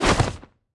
Media:sunburn_barbarian_atk_03.wav 攻击音效 atk 局内攻击音效
Sunburn_barbarian_atk_03.wav